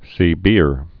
(sē-bēər)